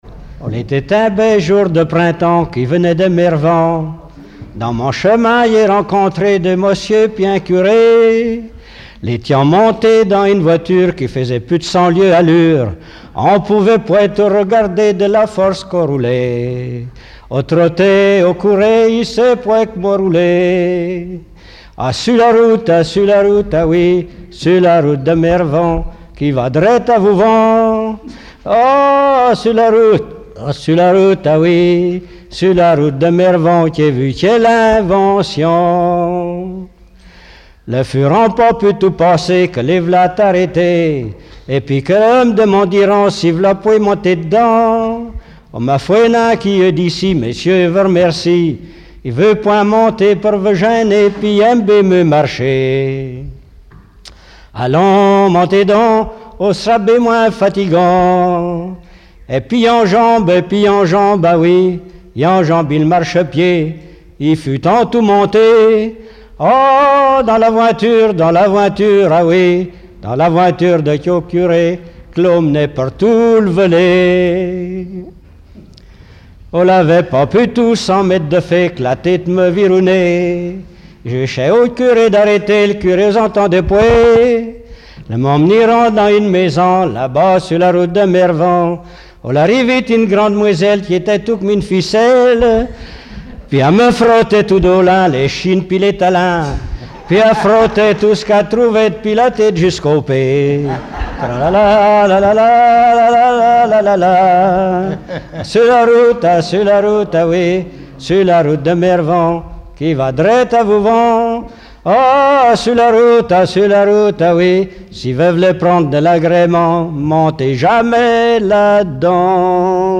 Regroupement au foyer logement
Pièce musicale inédite